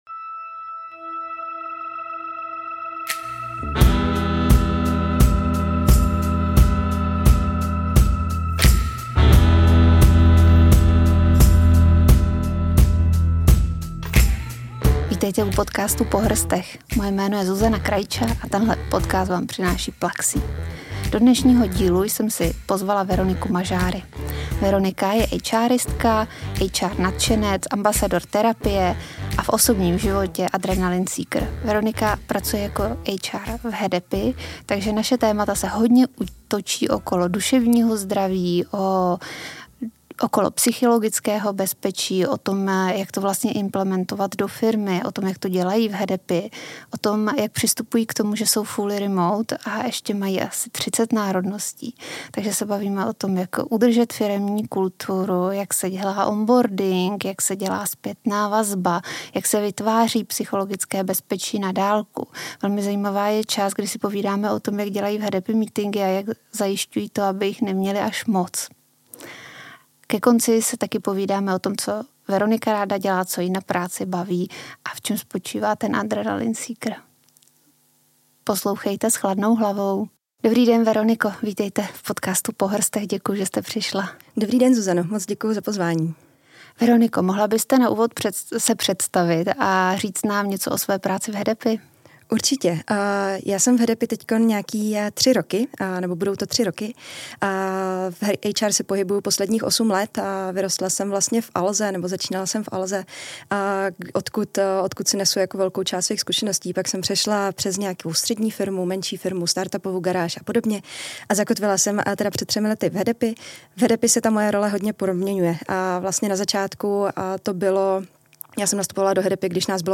V našem rozhovoru jsme se zaměřily na témata jako psychologické bezpečí, péče o duševní zdraví ve firmách a především na to, jak tato témata implementovat v praxi.